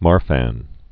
(märfăn)